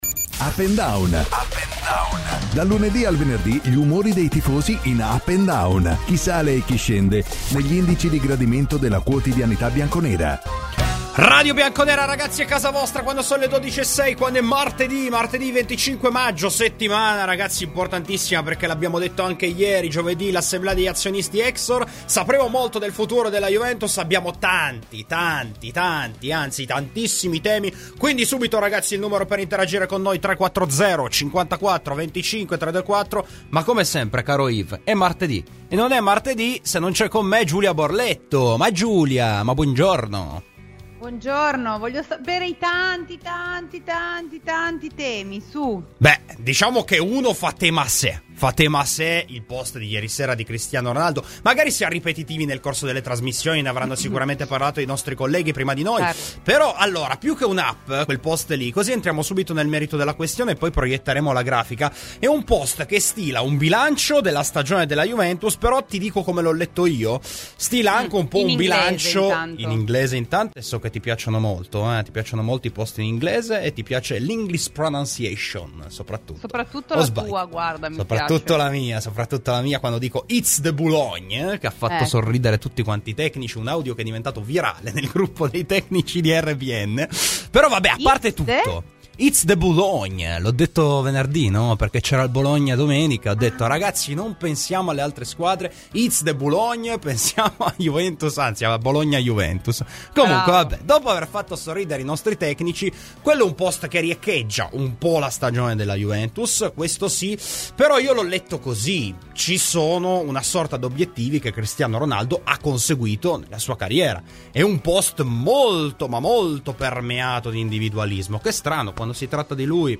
Ai microfoni di Radio Bianconera, nel corso di ‘Up&Down’, è intervenuto il comico Gene Gnocchi: “La Partita del Cuore?